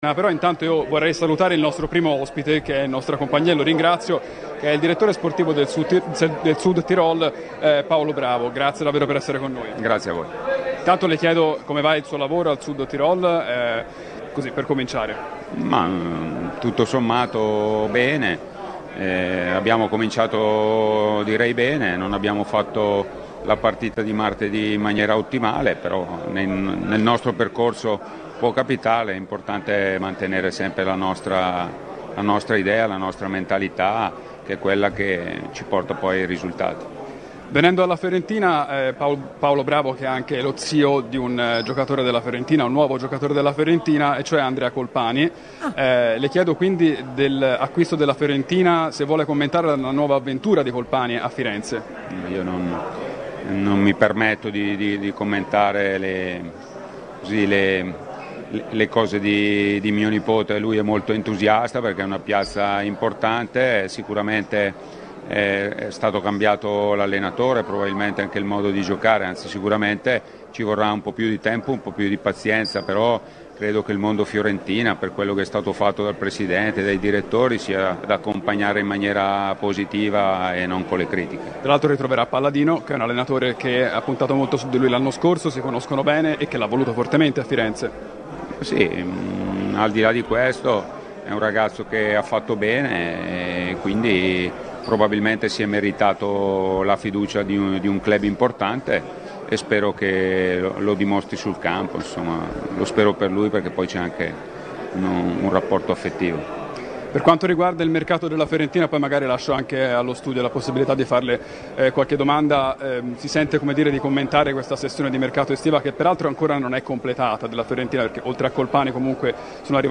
dalla sede del calciomercato in esclusiva a Radio FirenzeViola